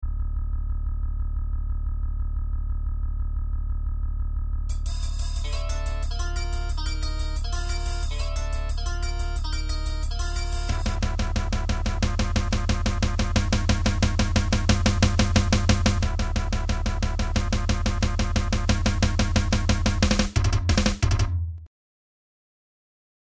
ちょっと成果のほどを・・・うｐ（打ち込み音源）
これは俺が作ったものを友達がちょっとアレンジしたやつ。
なんかあれだよね、ろけんろー。